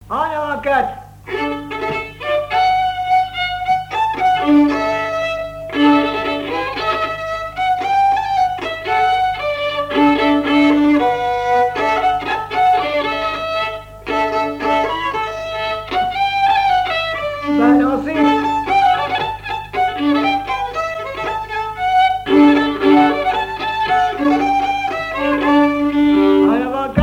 Mémoires et Patrimoines vivants - RaddO est une base de données d'archives iconographiques et sonores.
danse : quadrille : avant-quatre
Répertoire du violoneux
Pièce musicale inédite